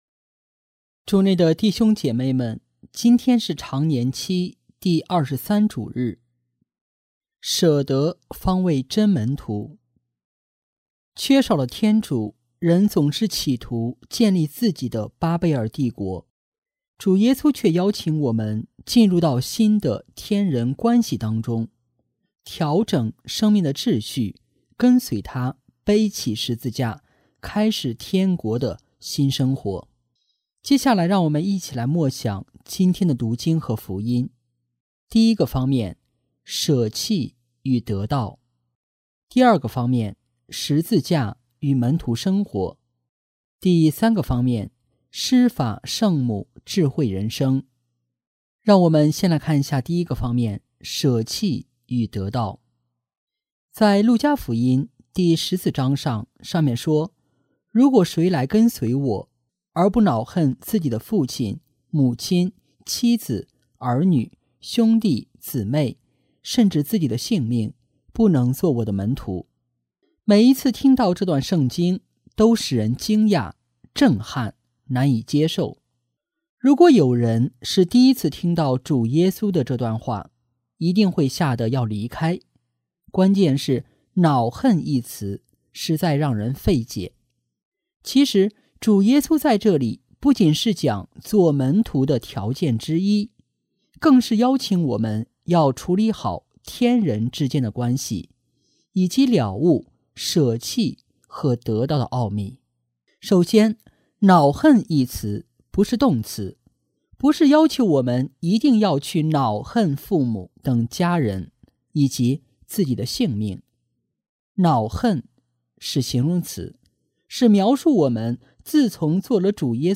【主日证道】| 舍得方为真门徒（丙-常年期第23主日）